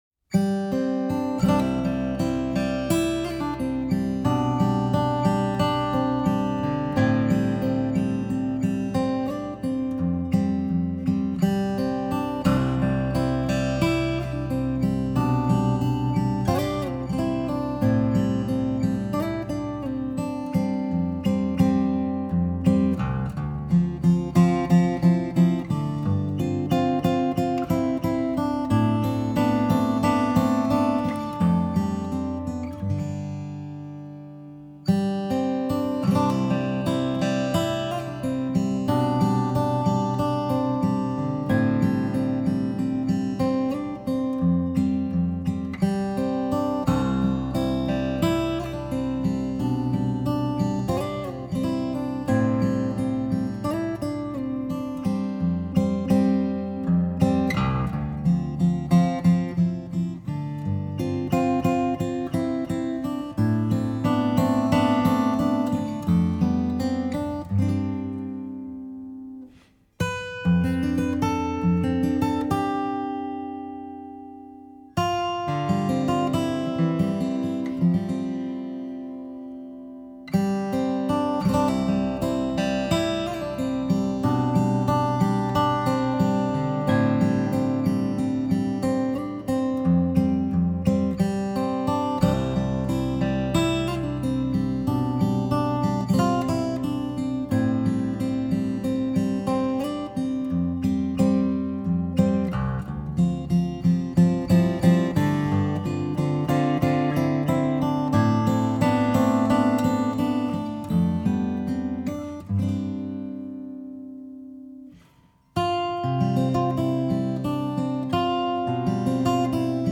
solo fingerstyle acoustic guitar album